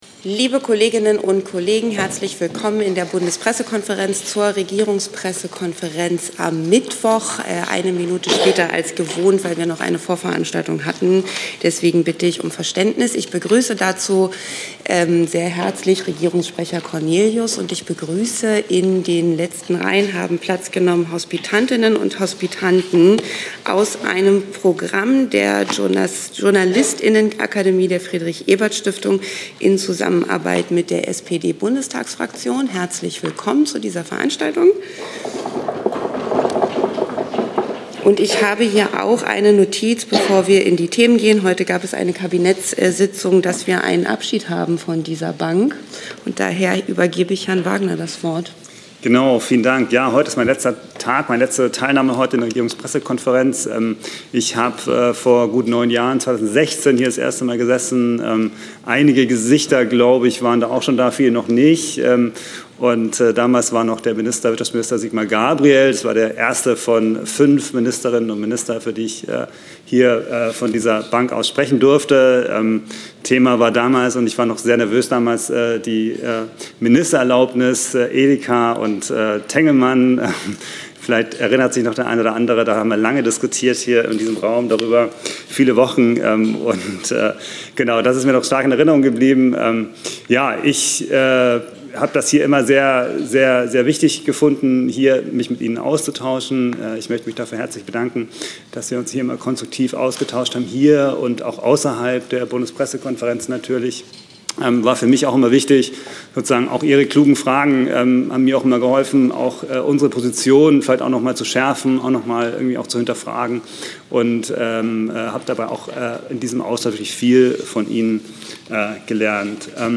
Regierungspressekonferenz in der BPK vom 5. November 2025